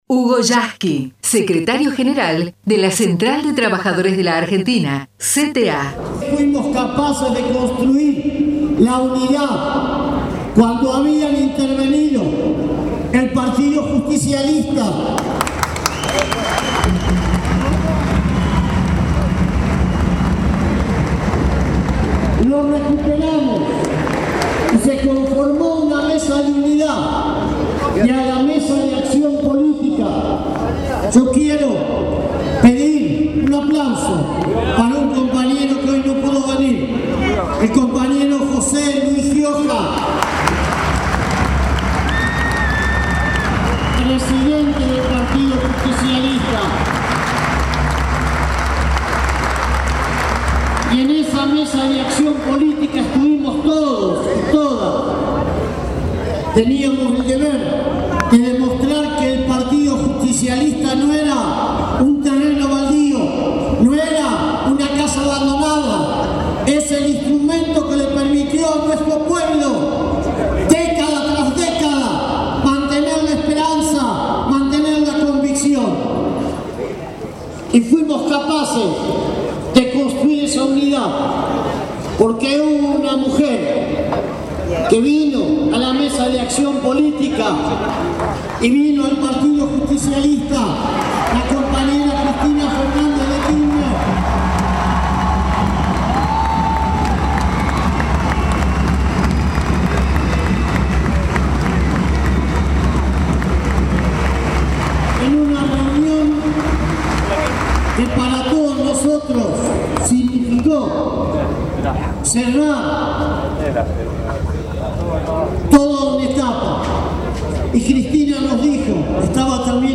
HUGO YASKY // Plenario Nacional CTA (MicroEstadio Lanús)